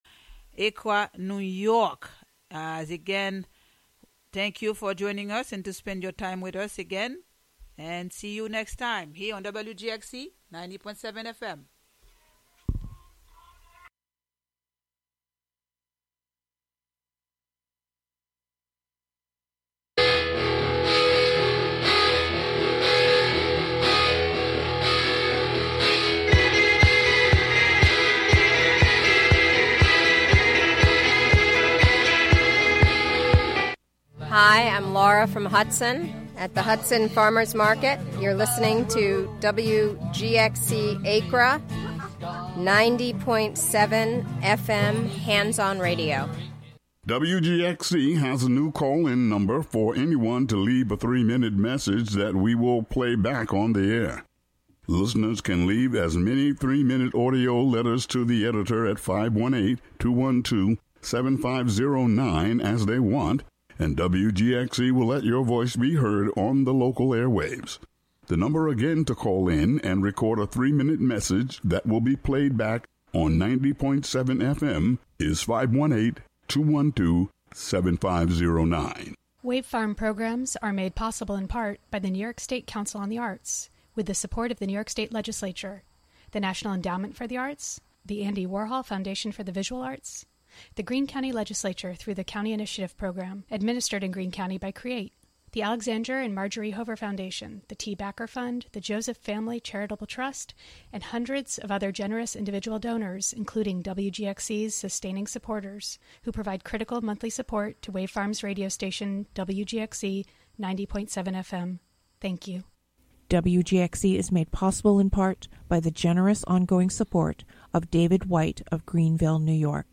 In this broadcast, an hour of parody, mockery, imitation, and homages. Monthly program featuring music and interviews from Dutchess County resident broadcast live from WGXC's Hudson studio.